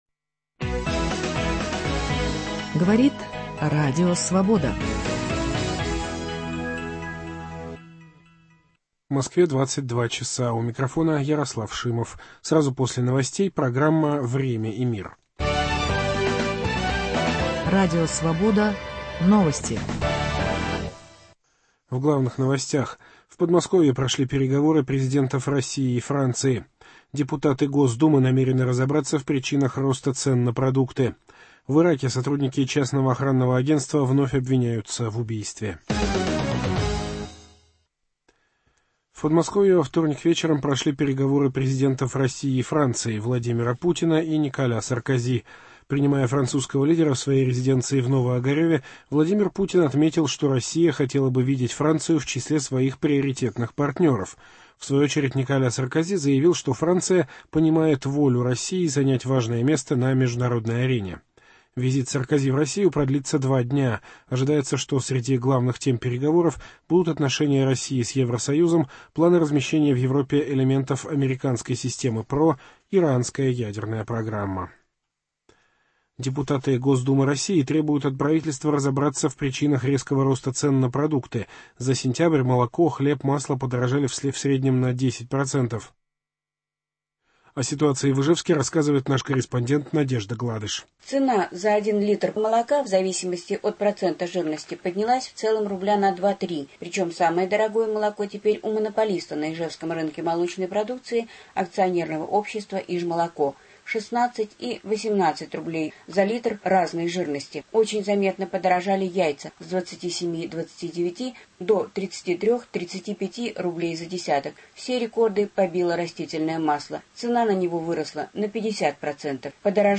Какие институты отсутствуют в России: интервью с Андреем Илларионовым.